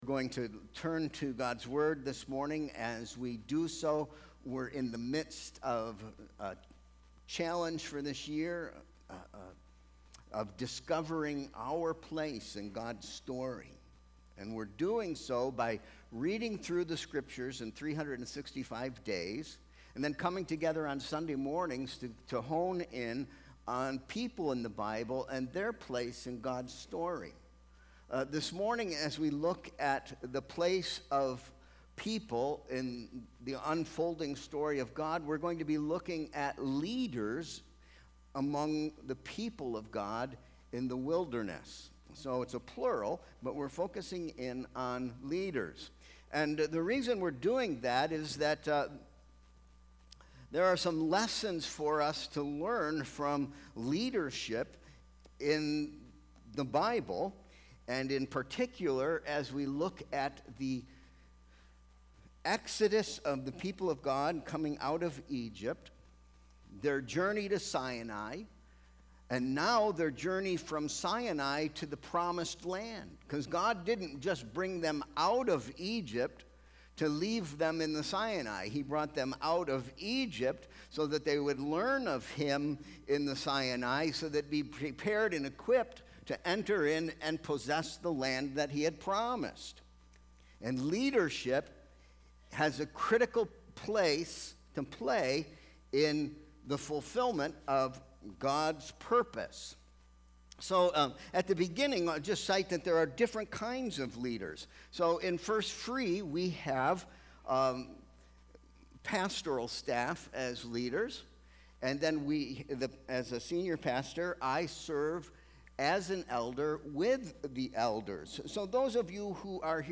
Sermon Question & Proposition: Do you know what leads your leaders?